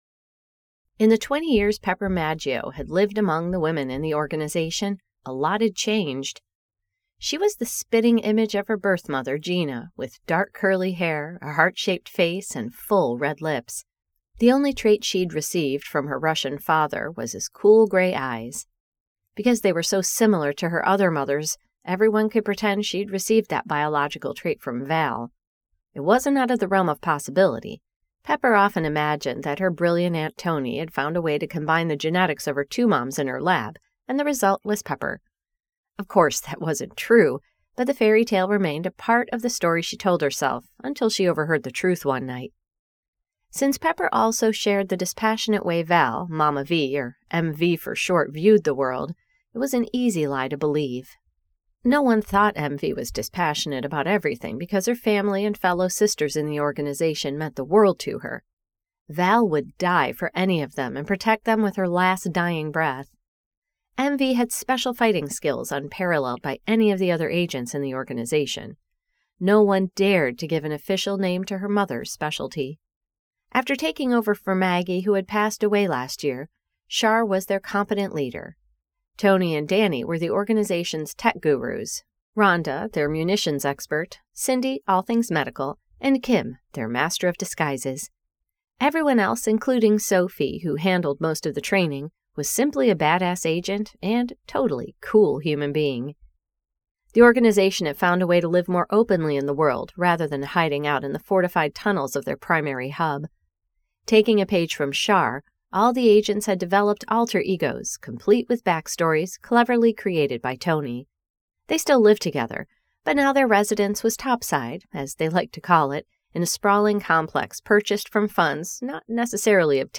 The Next Generation by Annette Mori [Audiobook]